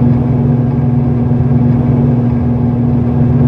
scpcb-godot/SFX/Ambient/Room ambience/Fan.ogg at e4012f8b7335c067e70d16efd9e1b39f61021ea4
Fan.ogg